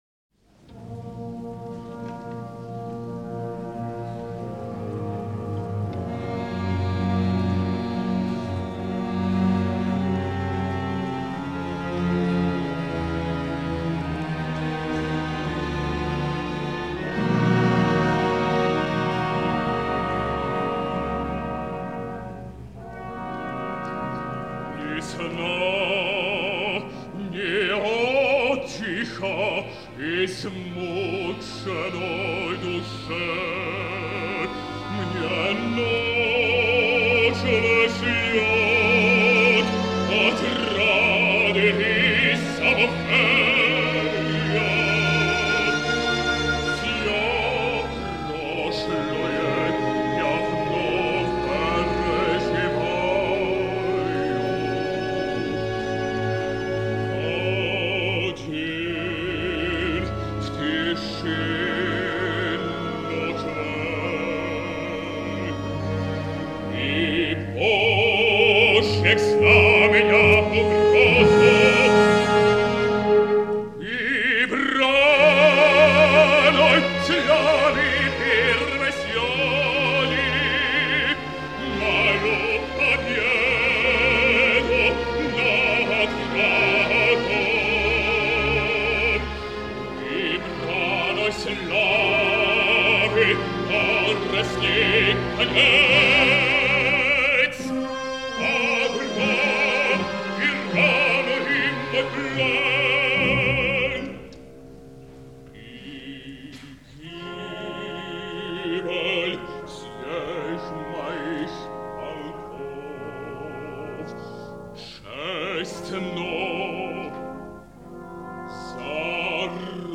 Bass-baritone. Alexander Borodin: Prince Igor’s aria (Act II).
Orchestre de la Suisse Romande.